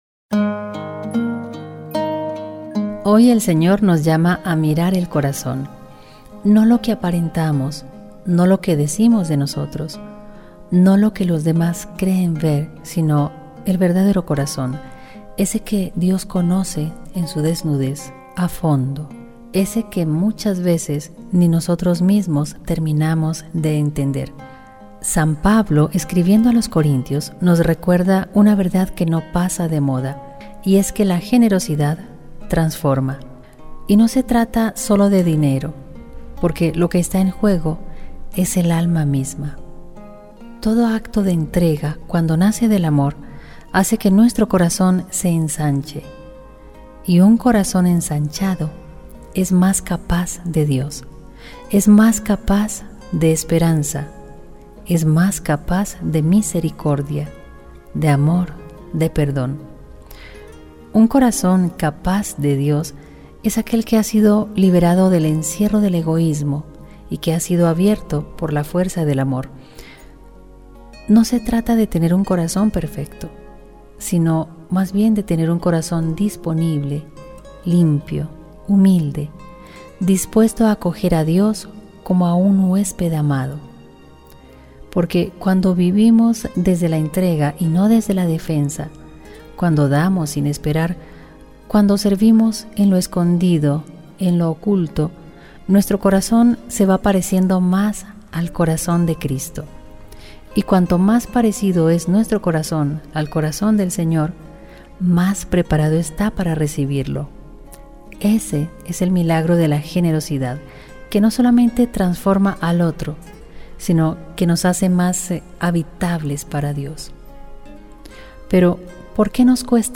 Reflexión para el miércoles XI del tiempo ordinario